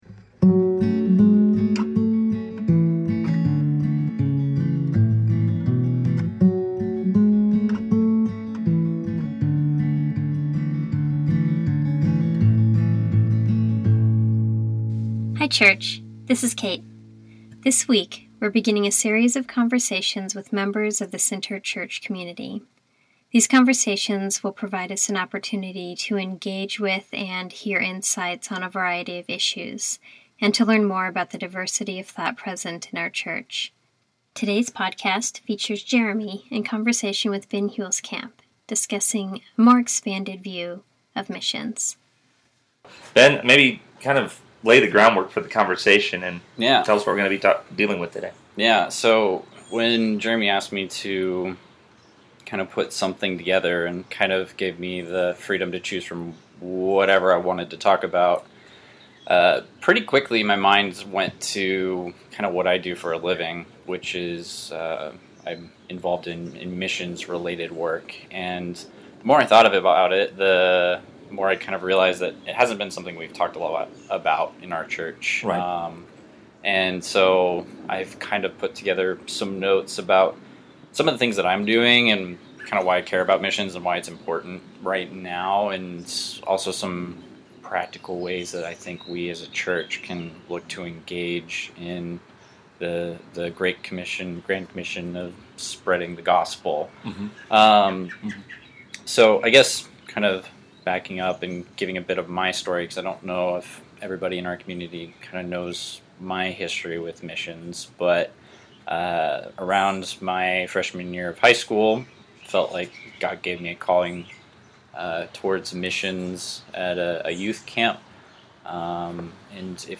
An Expanded View of Missions | A Conversation